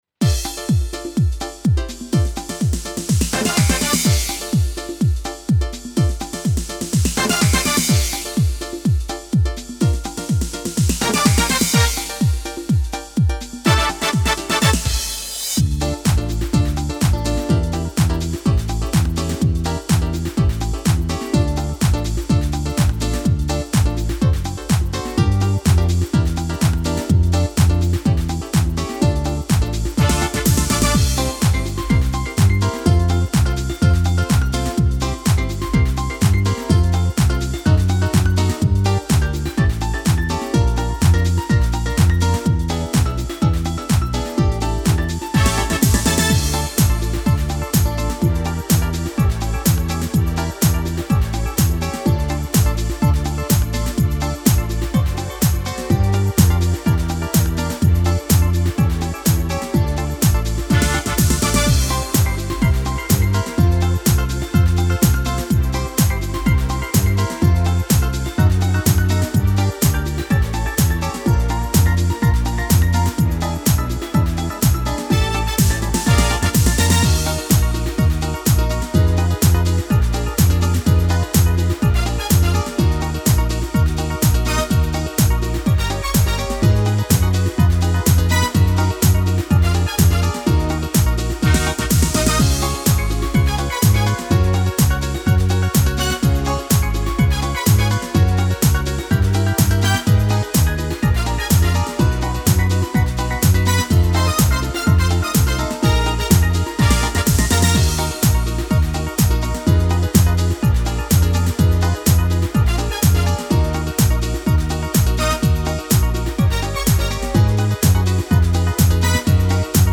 караоке
Скачать минус детской песни
минусовка